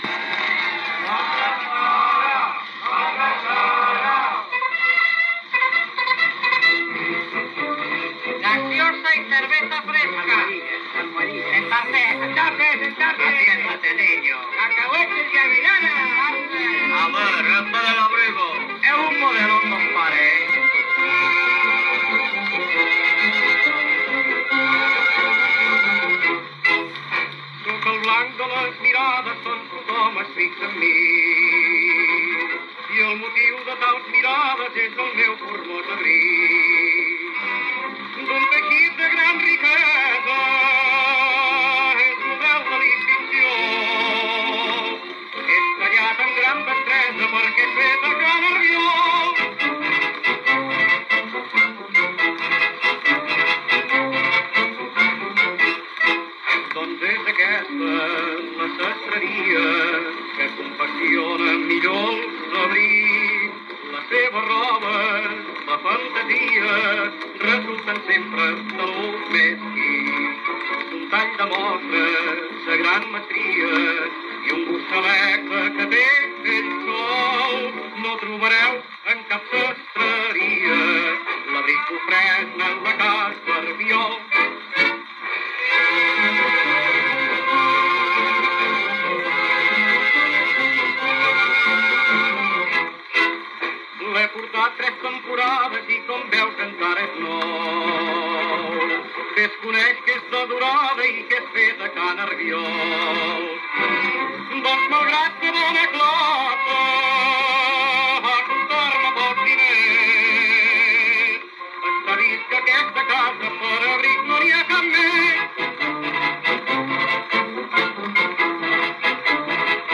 Interpretada a ritme de pasdoble.